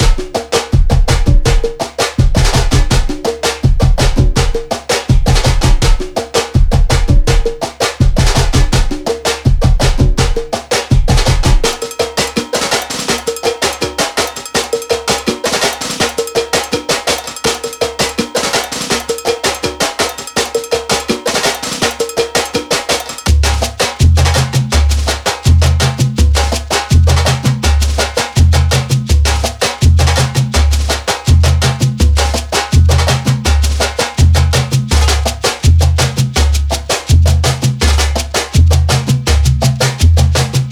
Biblioteca vibrante de Axé com loops de congas, malacaxeta e xequerê, trazendo a energia do Chicabana direto para suas músicas..
Loops Gerais, Multi Mix e solos de instrumentos como Conga, Cowbell, Malacaxeta e Timbal.